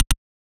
switch_004.ogg